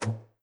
A2-key_click_110Hz.wav (38.5 KB)